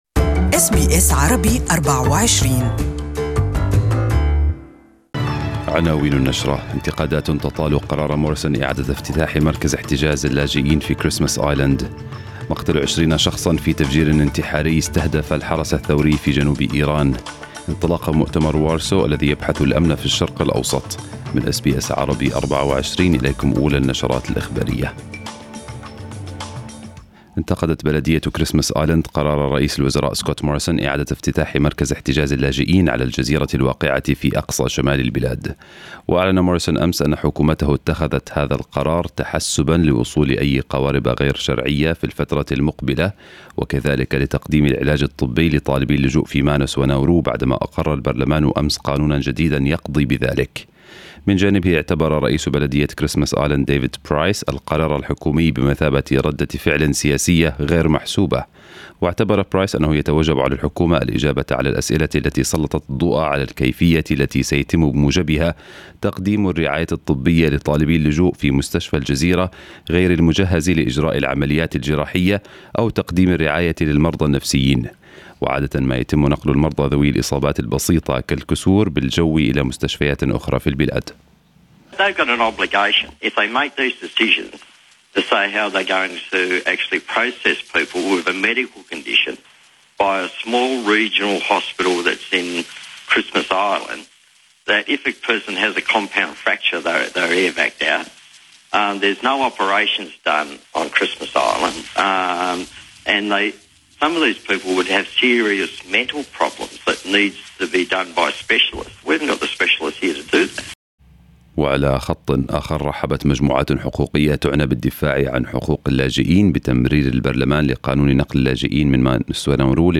News bulletin for the day in Arabic